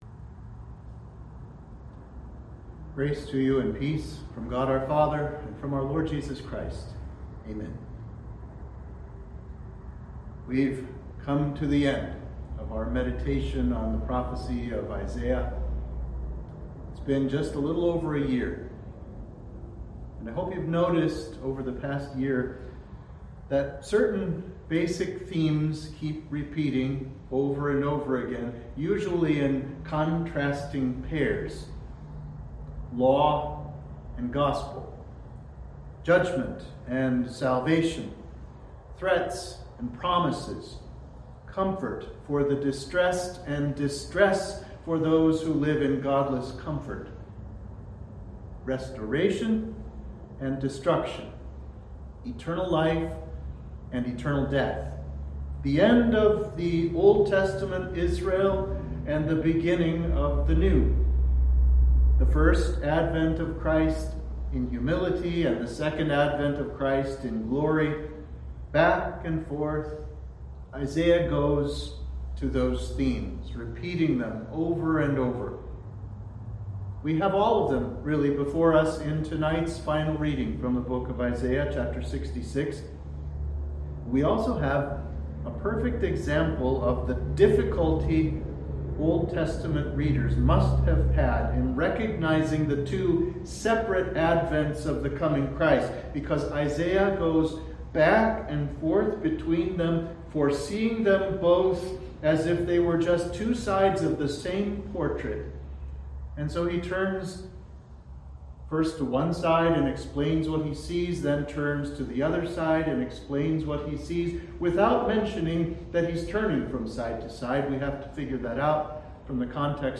Sermon Download Sermon We’ve come to the end of our meditation on Isaiah’s prophecy.